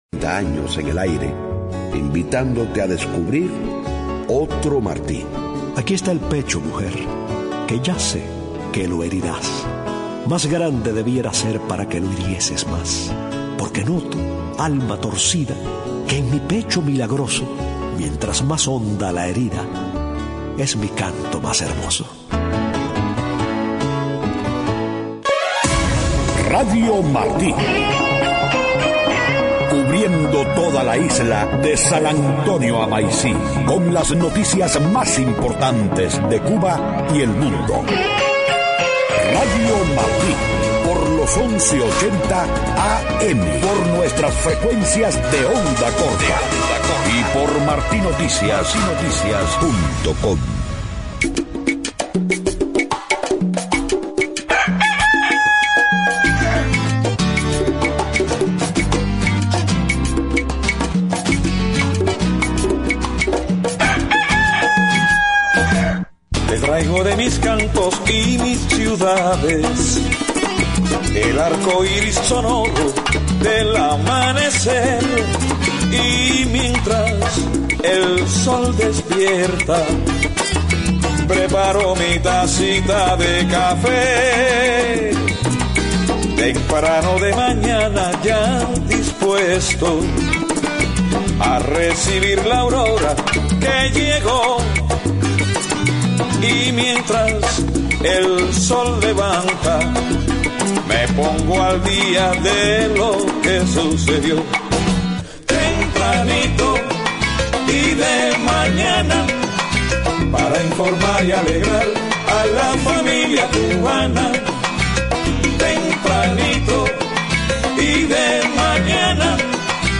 7:00 a.m Noticias: Opositores cubanos expresan ante el Congreso de EEUU su desacuerdo con la política de acercamiento con Cuba.